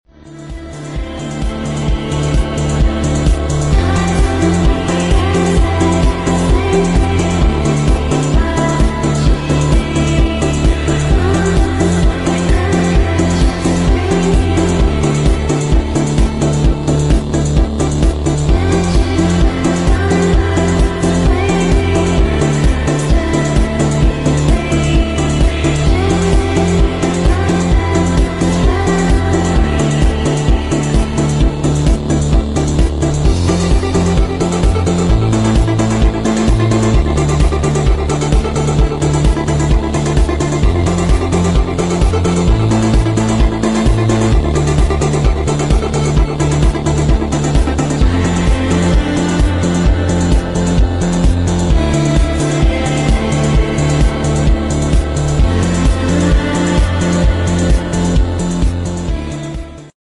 A350 Landing Sound Effects Free Download